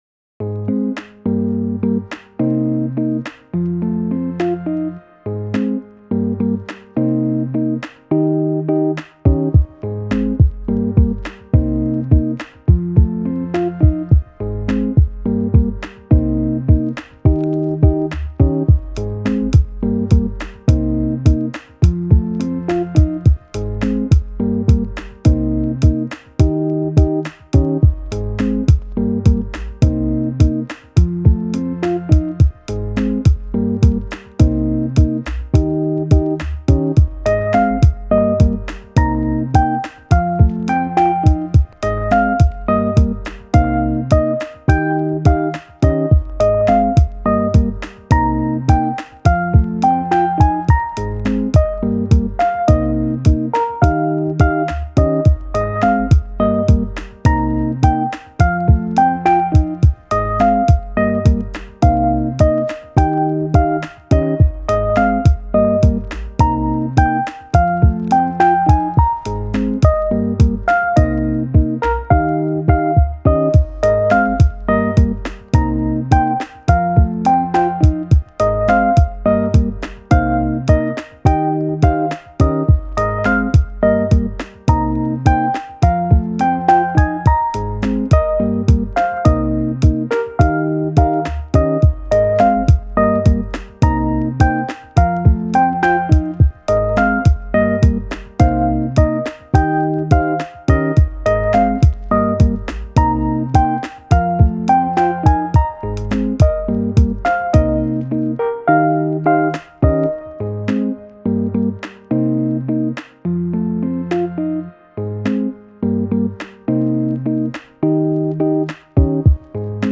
カフェミュージック チル・穏やか